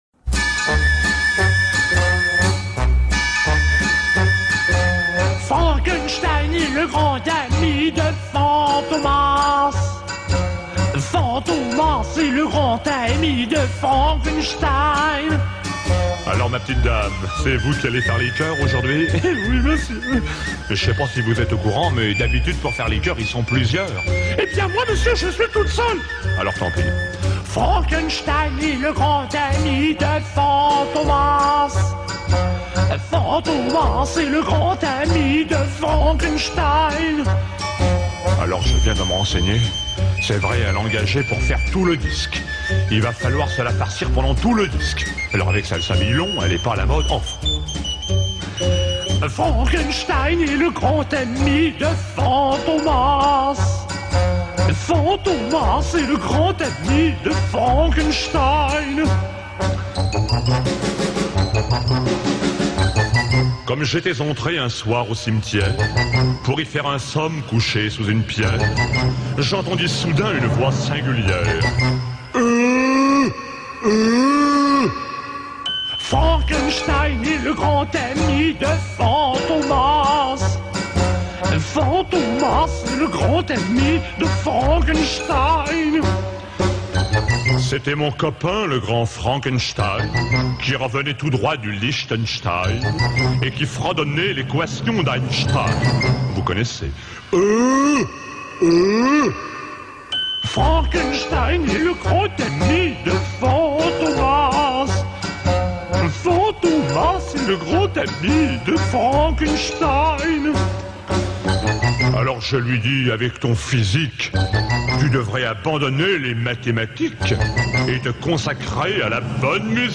poussant même des grognements associés au monstre
avec une voix fluette